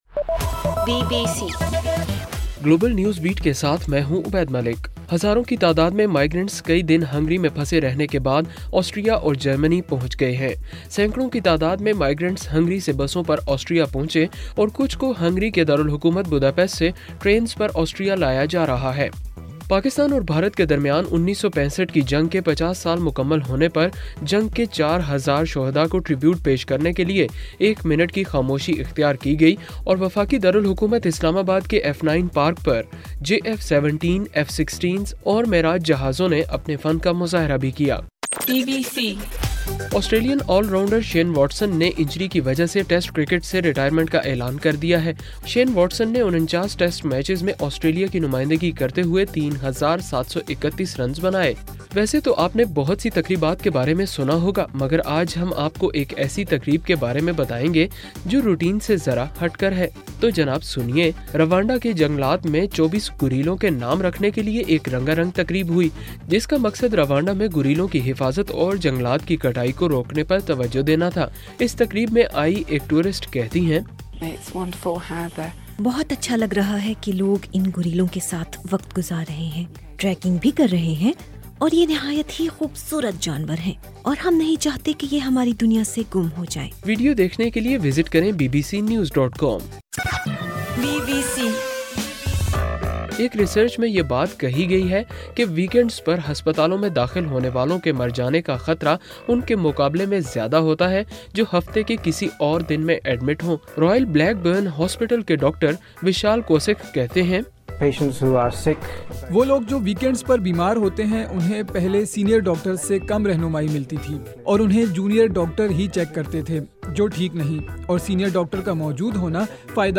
ستمبر6: رات 8 بجے کا گلوبل نیوز بیٹ بُلیٹن